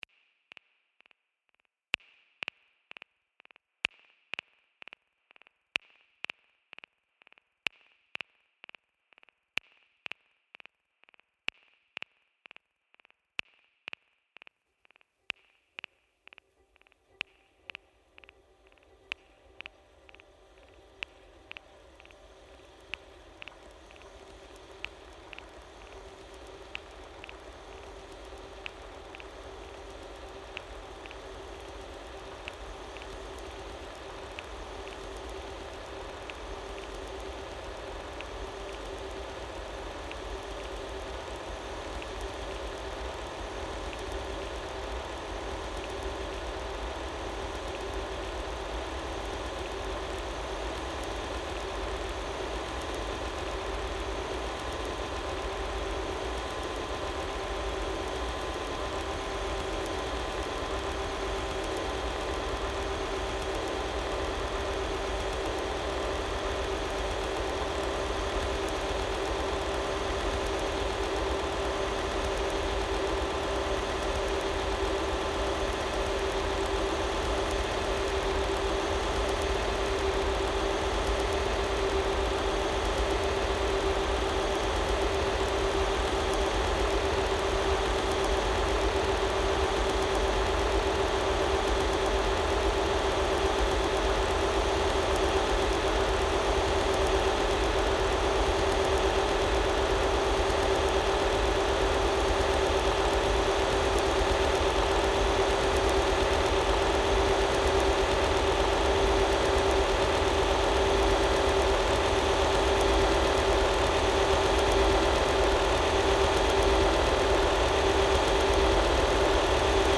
Evening Rain along the Wild Pacific Trail (Field Recording 17)